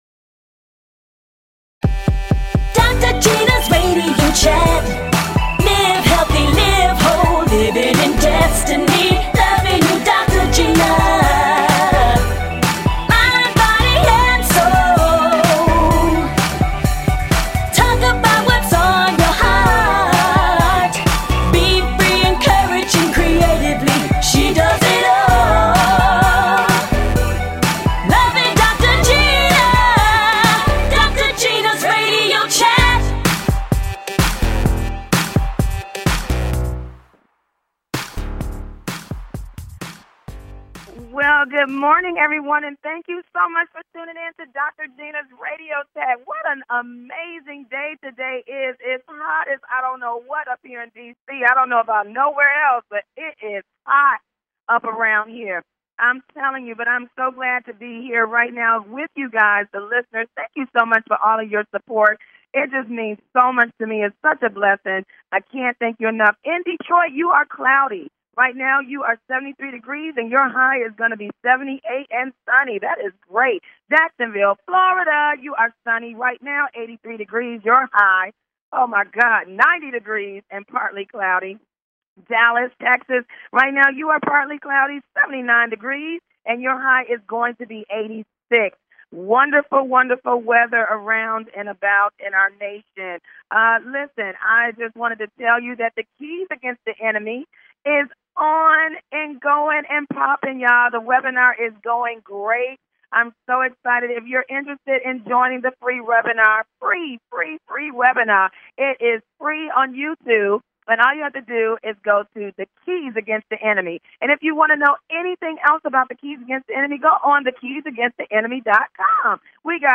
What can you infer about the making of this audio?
Exciting! And full of laughter!